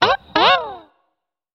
دانلود افکت صدای سوت گرگی
Sample rate 16-Bit Stereo, 44.1 kHz
Looped No